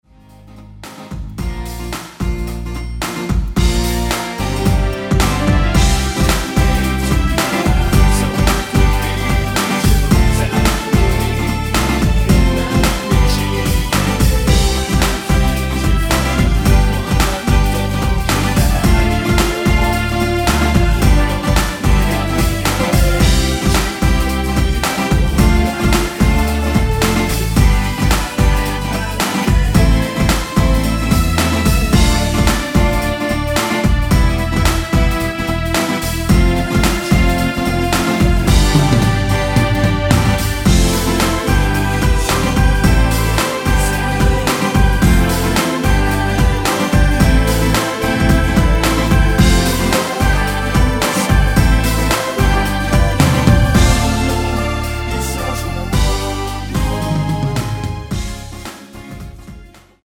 원키에서(-1)내린 코러스 포함된 MR입니다.
Eb
앞부분30초, 뒷부분30초씩 편집해서 올려 드리고 있습니다.
중간에 음이 끈어지고 다시 나오는 이유는